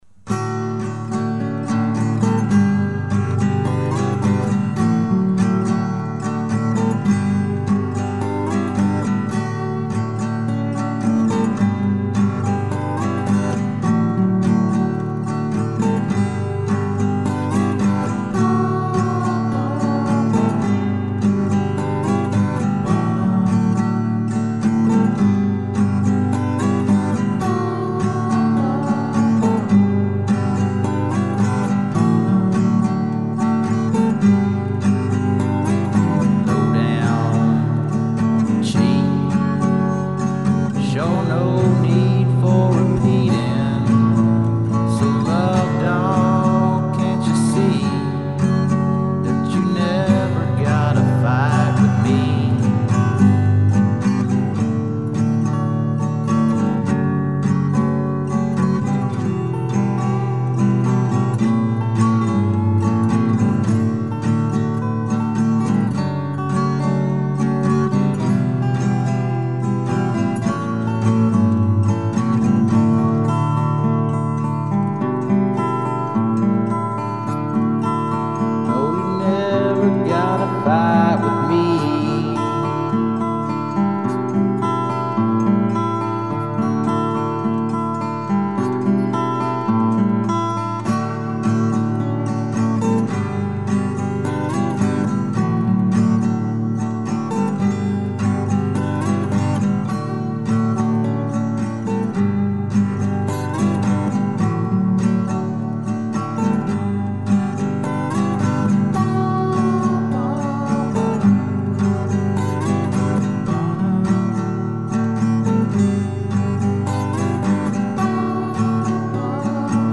Korg D1200 Digital Recording Studio